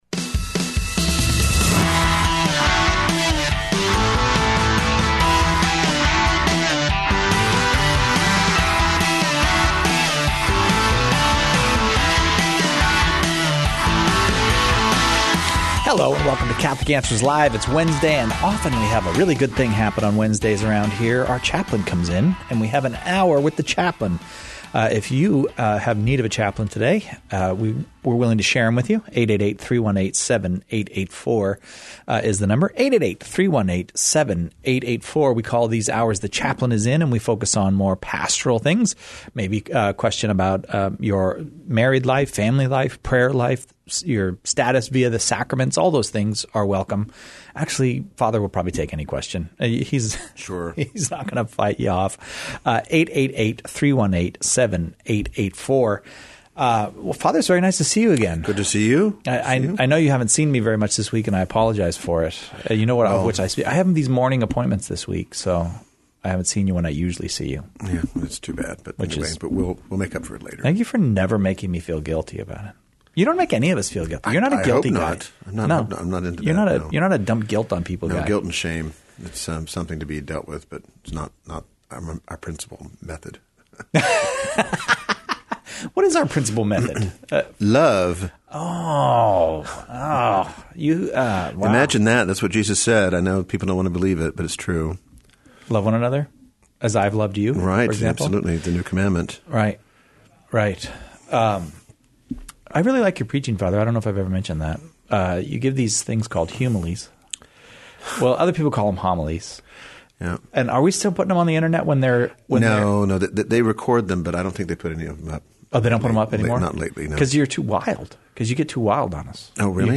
welcome any question callers would like to ask a Catholic priest